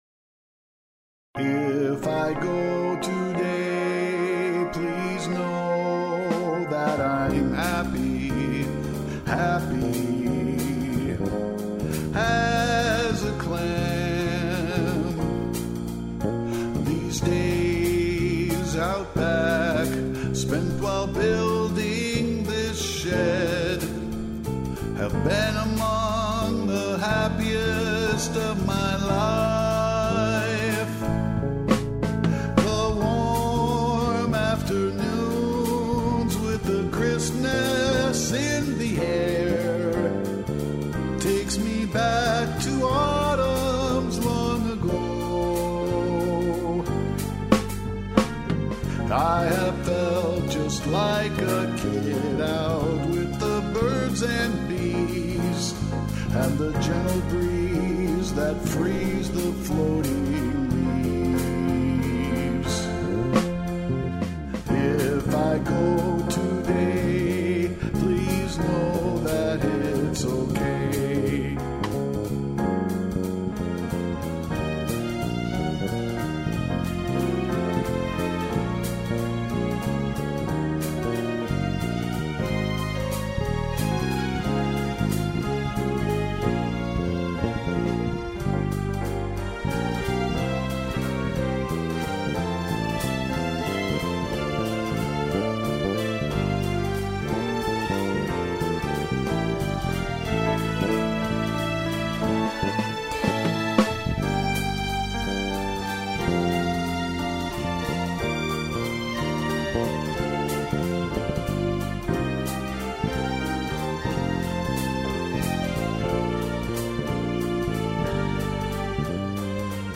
Trumpet
Bass